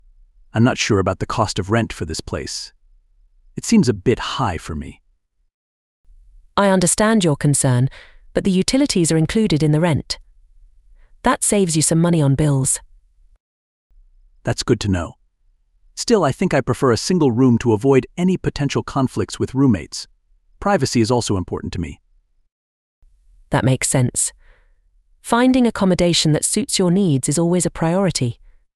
You will hear three conversations.